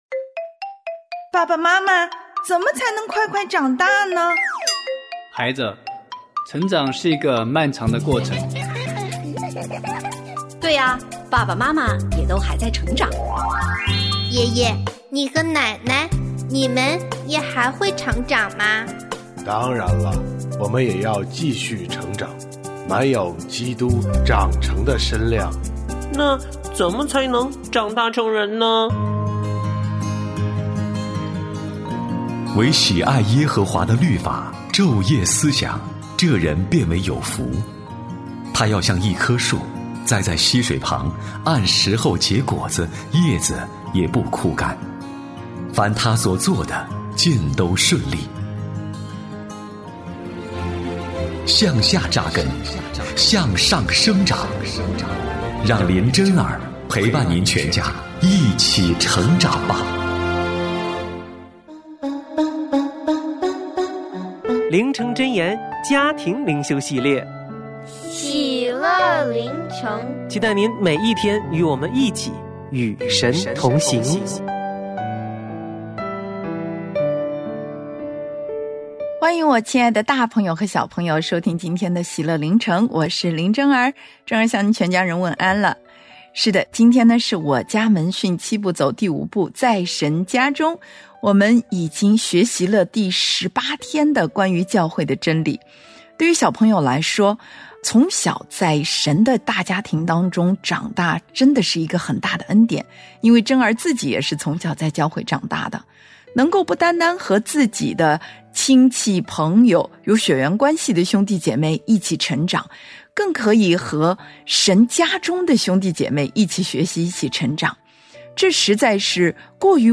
我家剧场：圣经广播剧（114）所罗门宣告建造圣殿的理由；所罗门献殿的祷告